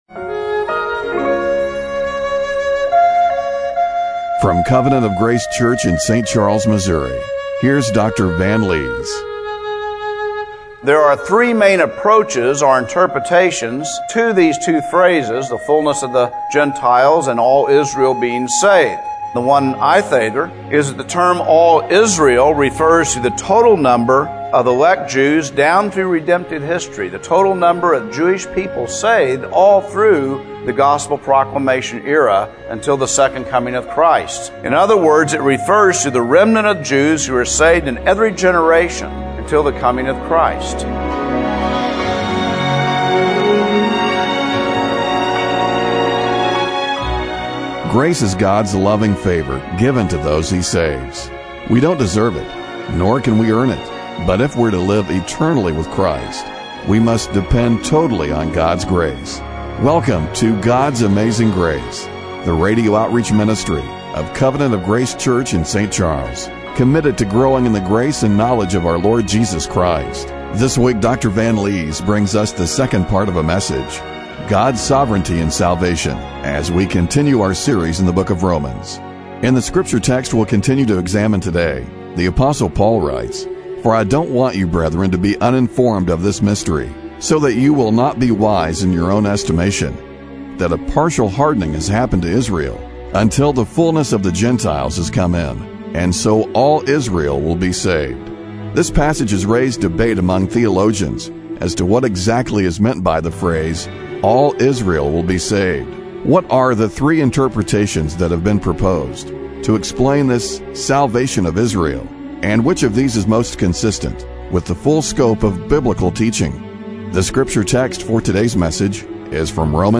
Service Type: Radio Broadcast